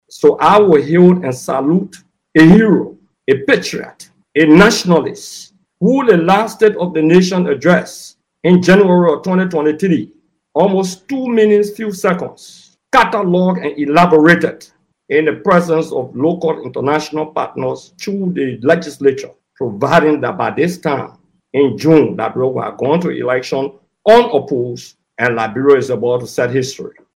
Gray made the claim at (1:56:28) of the show while criticizing President Boakai for not acknowledging the role of former President Weah who initiated Liberia’s bid for a UNSC seat.